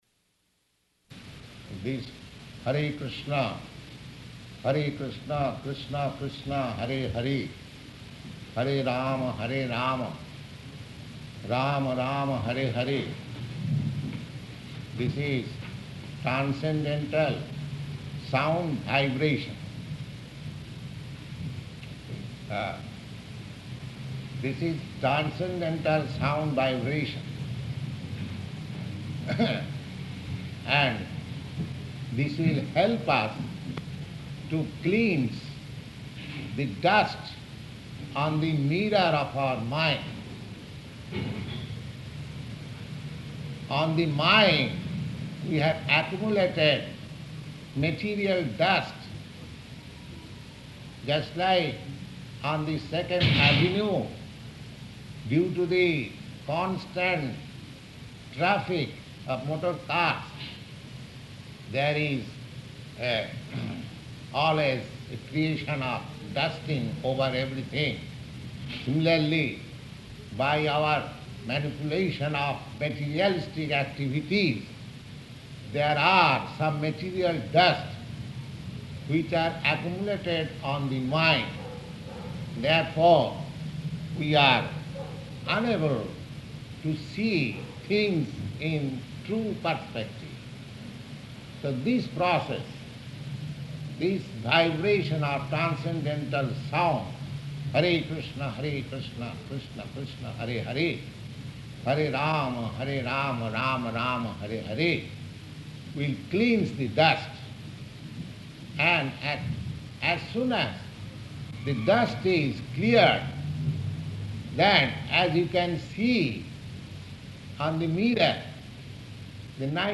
Type: Bhagavad-gita
Location: New York